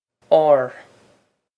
ár awr
This is an approximate phonetic pronunciation of the phrase.